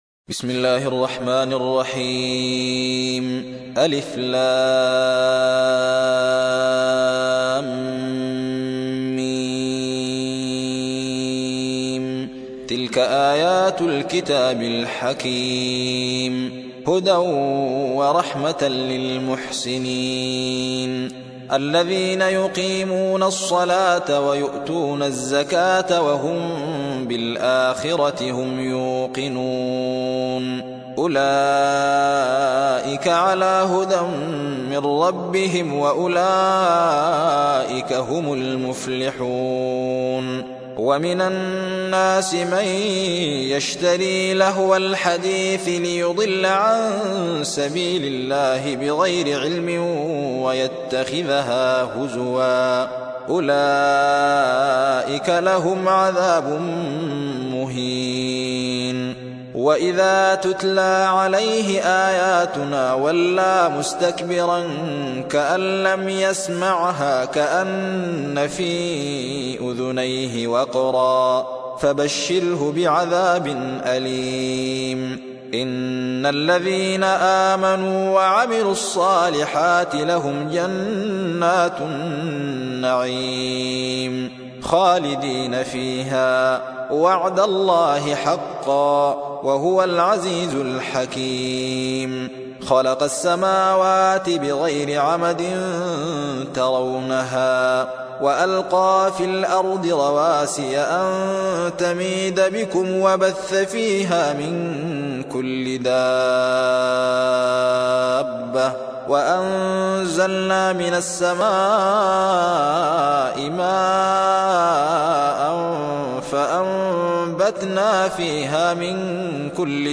31. سورة لقمان / القارئ